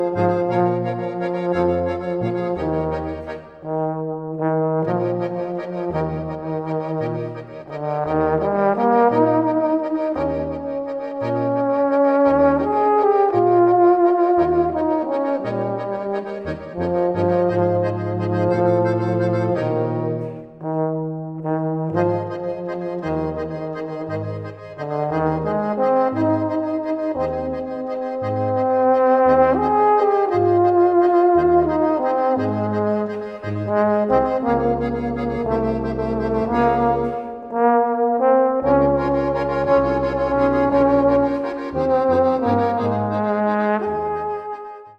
Besetzung: Volksmusik/Volkstümlich Weisenbläser
Akkordeon
Trompete
Tuba
Basstrompete